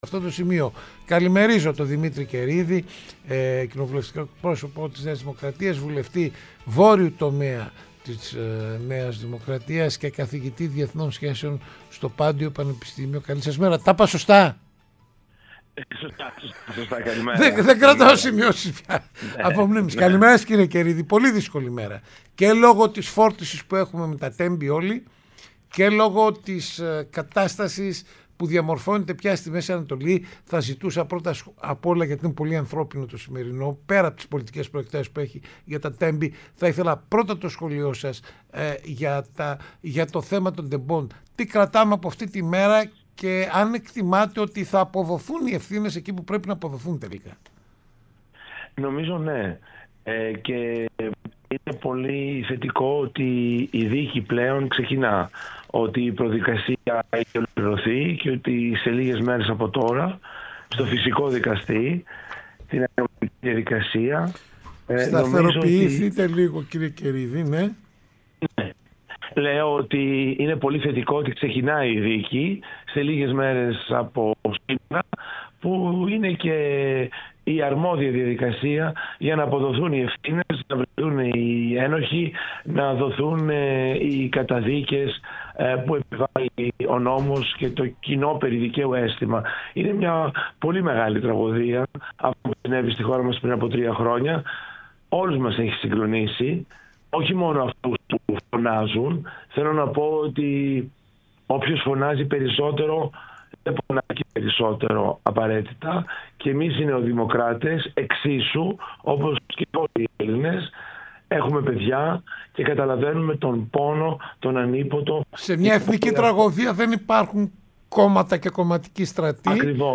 Ο Δημήτρης Καιρίδης στο ΕΡΤnews Radio 105,8 | 28.02.2026